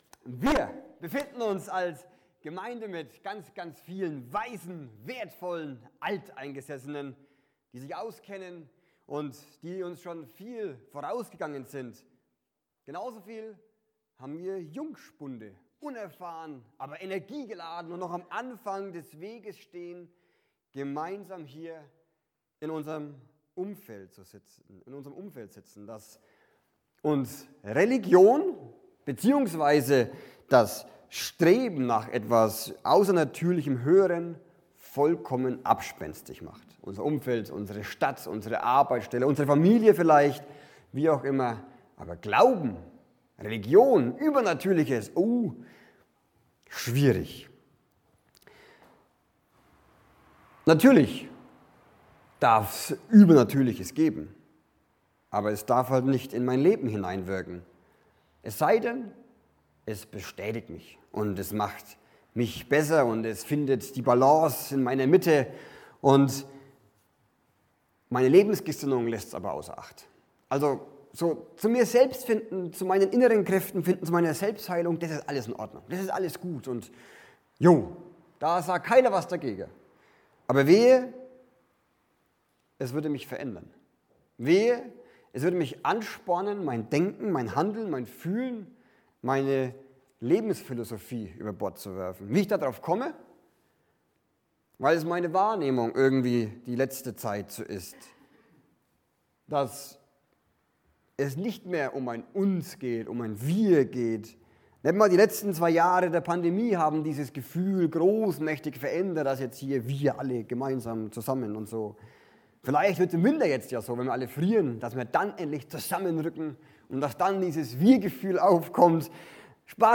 predigte